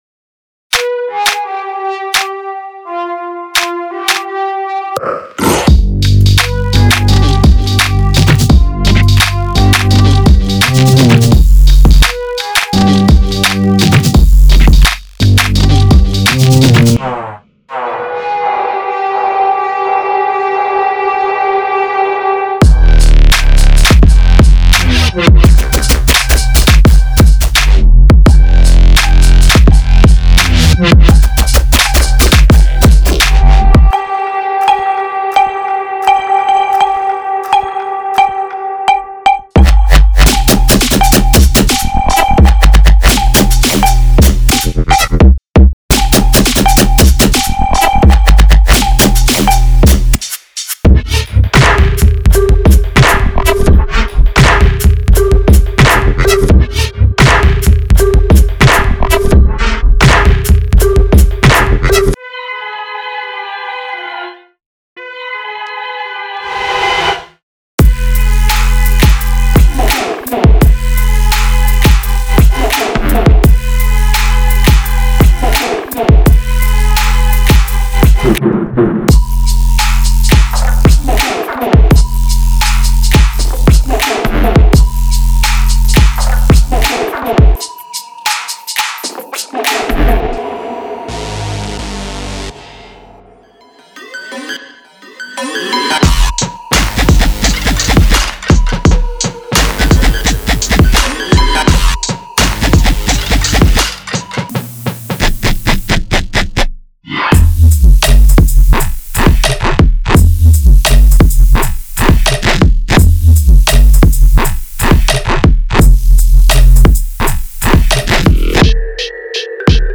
.5个乙烯基噪音